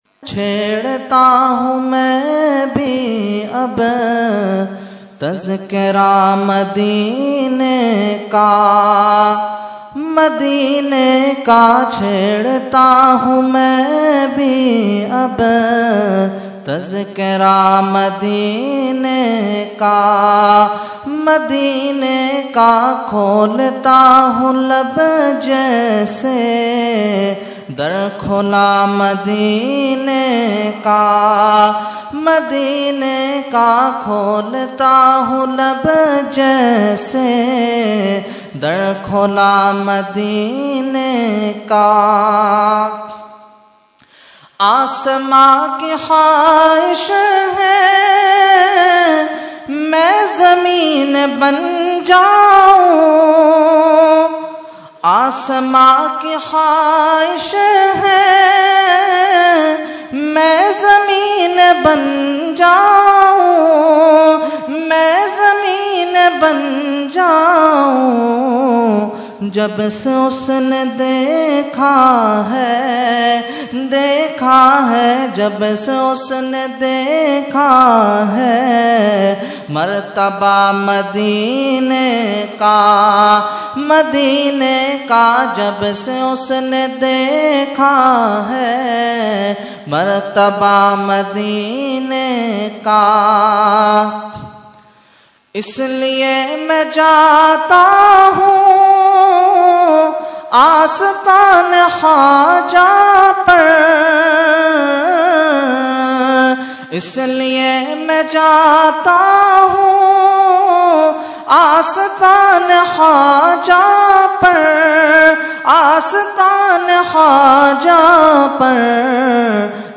Category : Naat | Language : UrduEvent : 11veen Shareef 2016